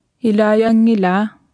Martha tassaavoq kalaallisut qarasaasiakkut atuffassissut.